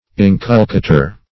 inculcator - definition of inculcator - synonyms, pronunciation, spelling from Free Dictionary Search Result for " inculcator" : The Collaborative International Dictionary of English v.0.48: Inculcator \In*cul"ca*tor\, n. [L.]